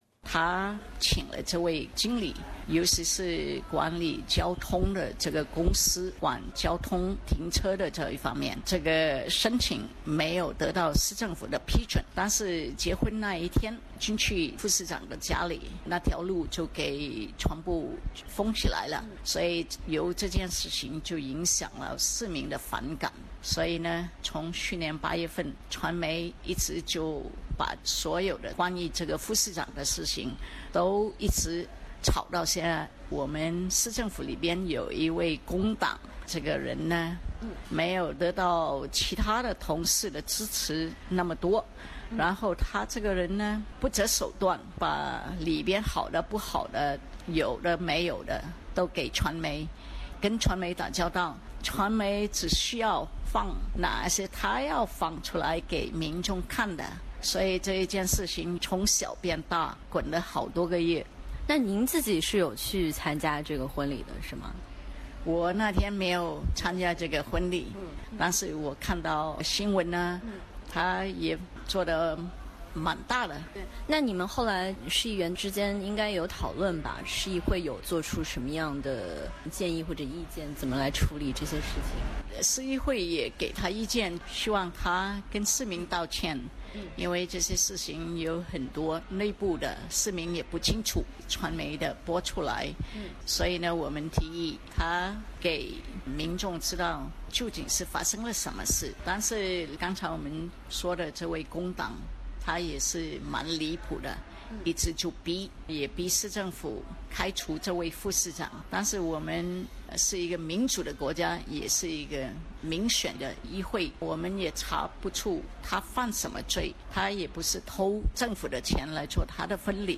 我眼里的Salim Mehajer- Auburn市长林丽华专访
她认为Salim是一个勤力，有教养的，负责任的人，并批评州政府故意找地方议会的问题，主要是因为要进行地方政府的合并。采访中，林丽华首先回忆了Salim那次震撼的"世纪婚礼"。 请听采访录音。